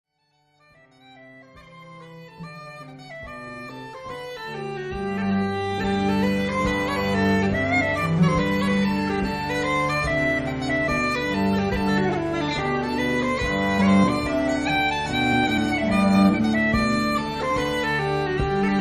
Musique flamande des XVIIe et XVIIIe siècles
... vue à traver la musique traditionnelle ...
violon, violon alto
viole de gambe
vielles à roue, épinettes, bodhran
musette baroque, uilleann pipes, doedelzak, pipeaux irlandais, cistre.